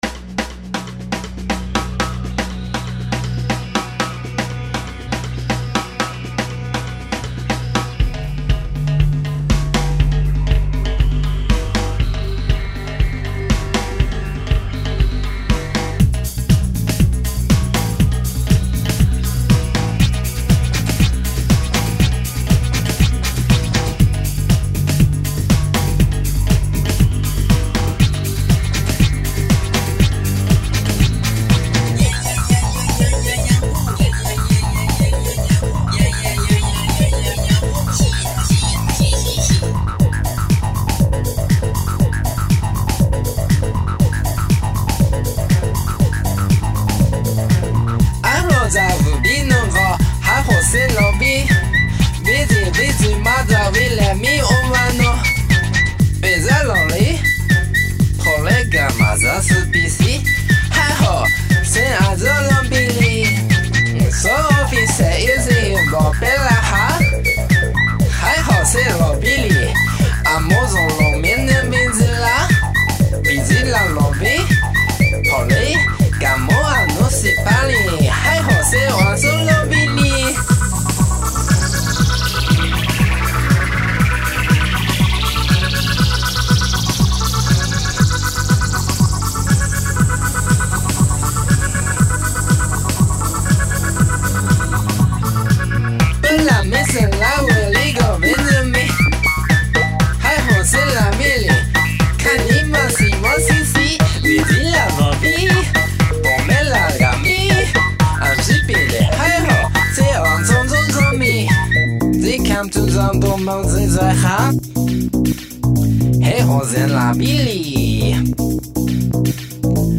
File under: Unsuitable Rock / Electro-Pop